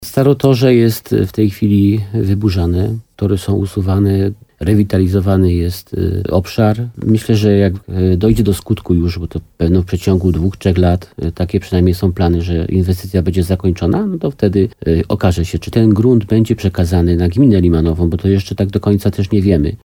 Jak mówi wójt gminy Limanowa Jan Skrzekut, plany budowy ścieżki rowerowej i tak są uzależnione od ewentualnego przejęcia przez samorząd działek od kolei.